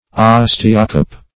Search Result for " osteocope" : The Collaborative International Dictionary of English v.0.48: Osteocope \Os"te*o*cope\, n. [Gr.